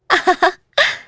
Isaiah-Vox_Happy2.wav